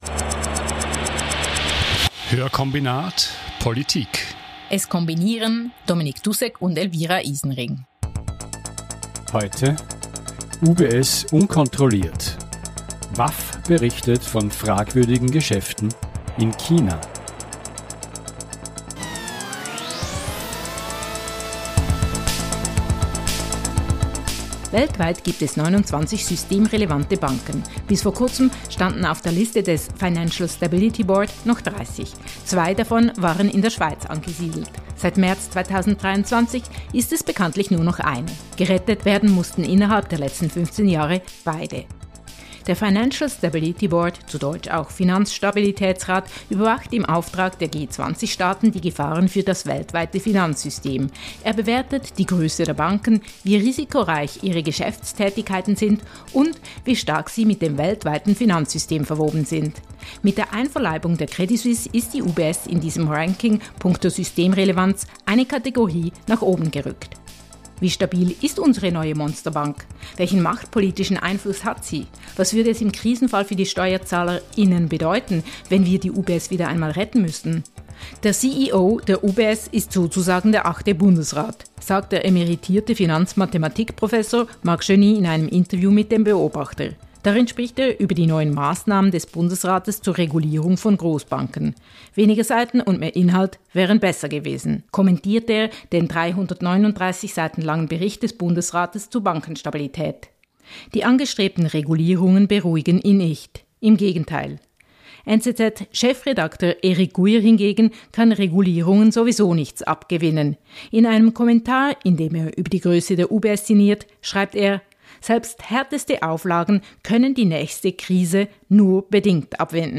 In jeder Folge bespricht das Audio-Duo Hörkombinat einen aktuellen Artikel mit einem/einer Journalist:in und ergänzt das Interview mit Hintergrundinformationen. Der Schwerpunkt liegt auf sozial- und wirtschaftspolitischen Themen.